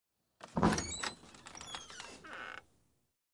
随机的 "木头门，旧的吱吱作响的摇摇欲坠的几乎是柳条的门。
描述：门木老吱吱作响摇摇晃晃几乎柳条open.wav